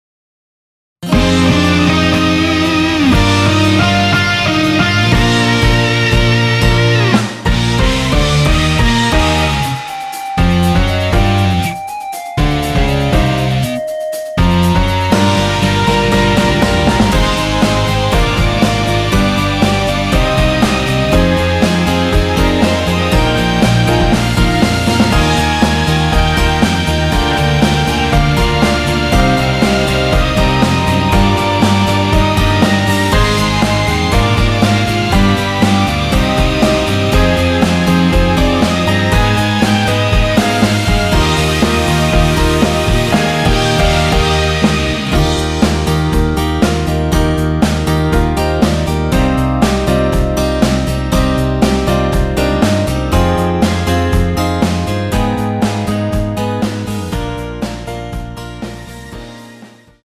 원키에서(+3)올린 멜로디 포함된 MR 입니다.(미리듣기 참조)
Eb
앞부분30초, 뒷부분30초씩 편집해서 올려 드리고 있습니다.
중간에 음이 끈어지고 다시 나오는 이유는